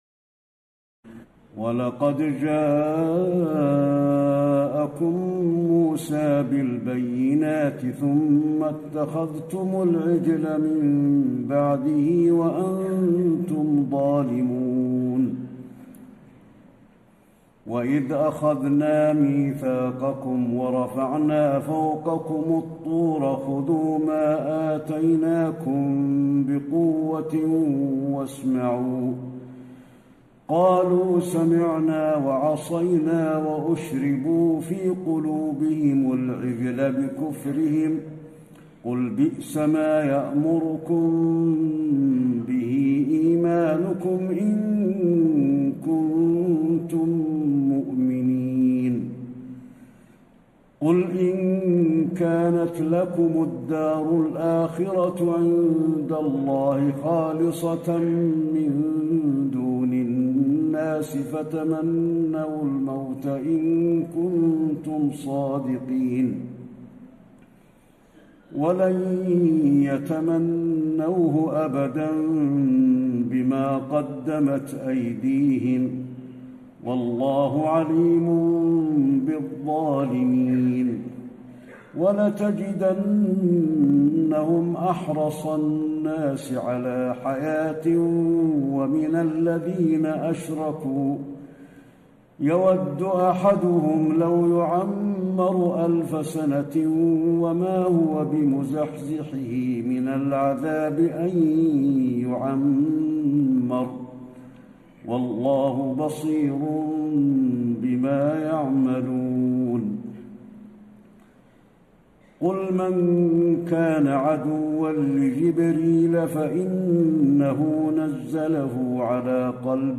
تهجد ليلة 21 رمضان 1434هـ من سورة البقرة (92-141) Tahajjud 21 st night Ramadan 1434H from Surah Al-Baqara > تراويح الحرم النبوي عام 1434 🕌 > التراويح - تلاوات الحرمين